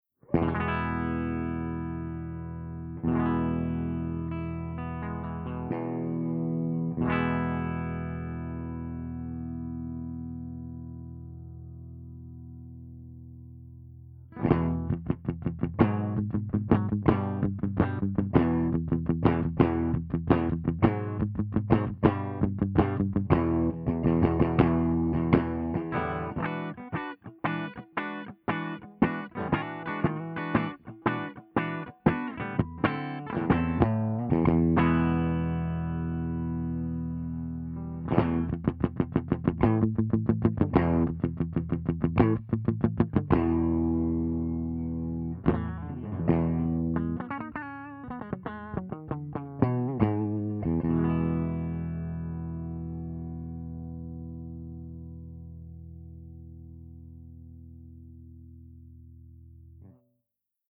040_FENDER75_WARM_BASSBOOST_HB
040_FENDER75_WARM_BASSBOOST_HB.mp3